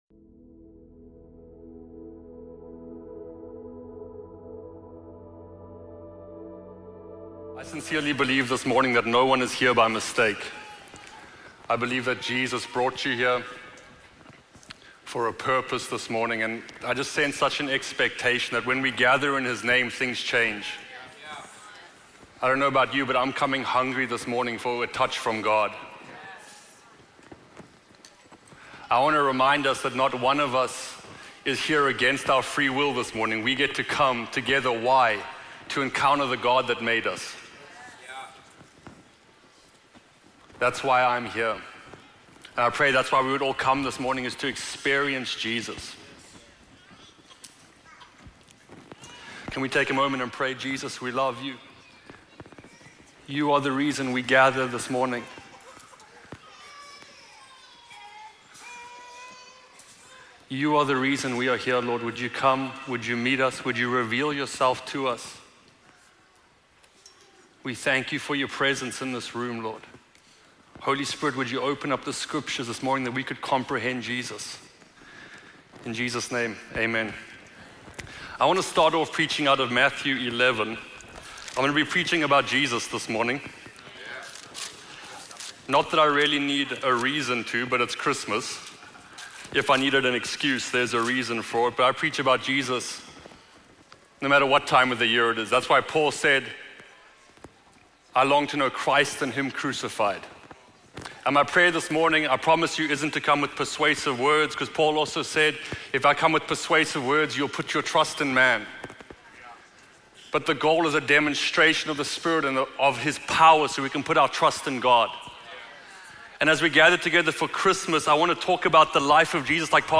A message on Jesus as the Lowly King, inviting us into humility, dependence, and true rest through the way of the cross.